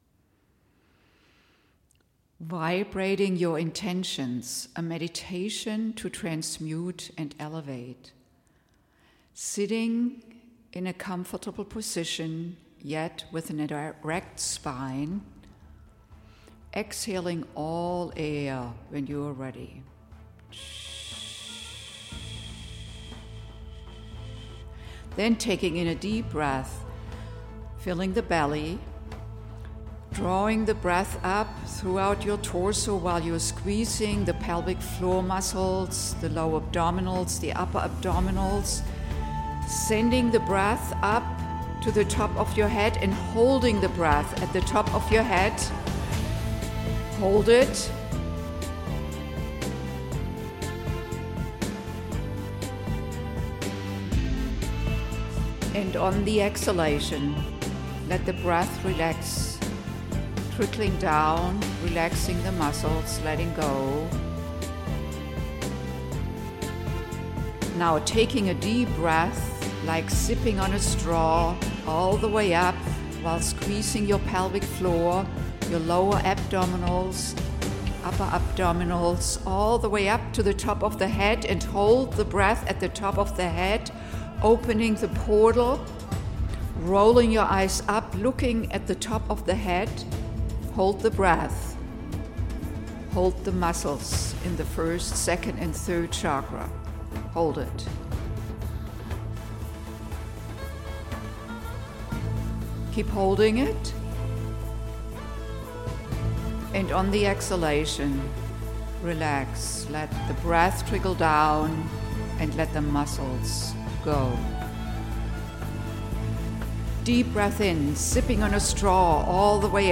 Vibrating Your Intention: A Meditation to Transmute and Elevate